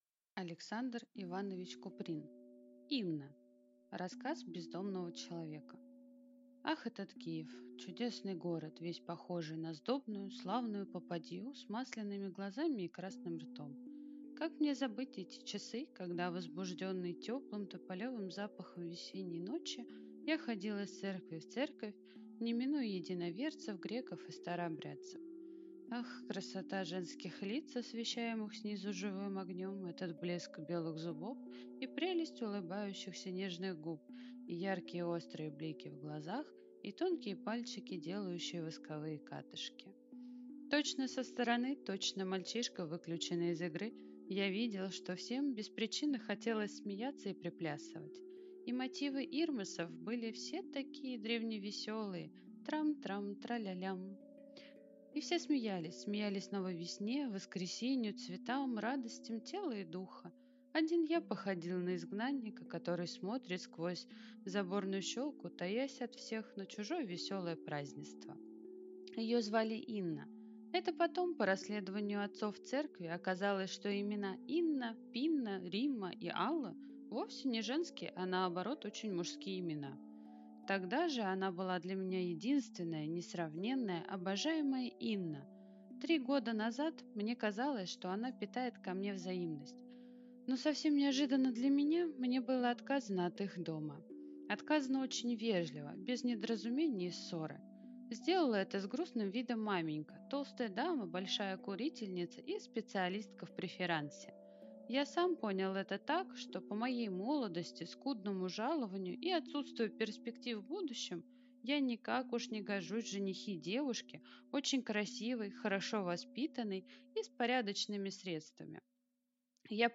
Aудиокнига Инна